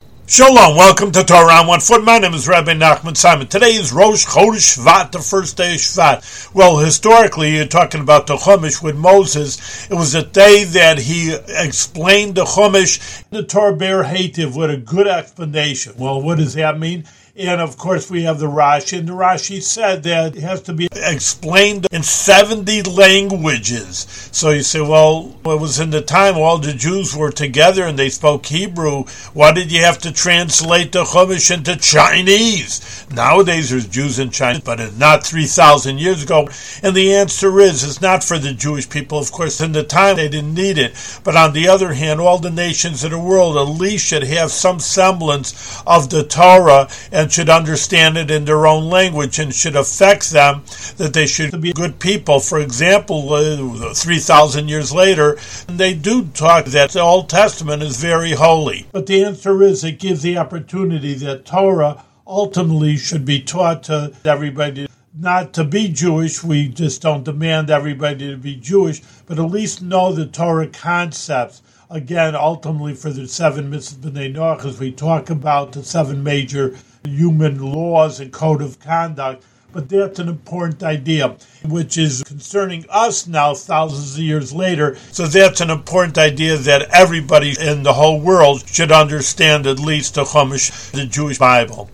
Scroll down for a short audio lesson